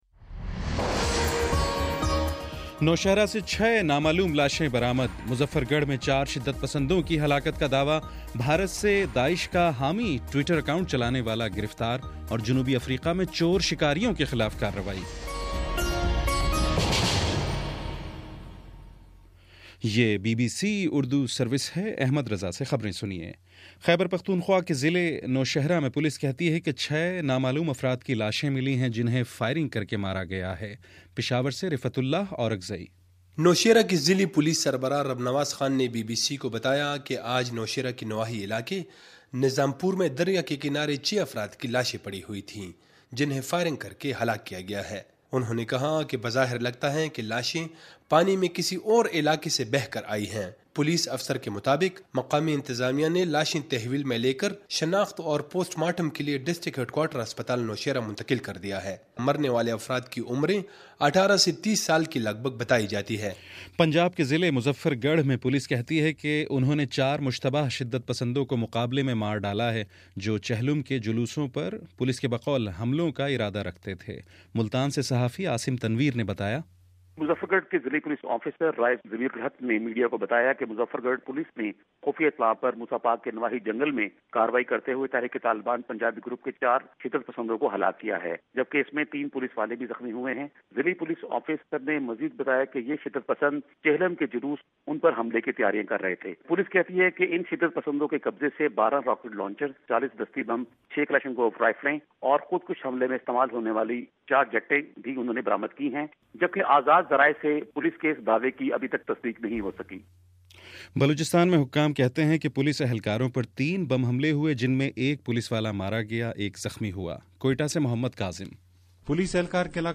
دسمبر13: شام چھ بجے کا نیوز بُلیٹن